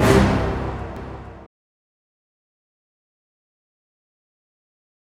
Hit (1).wav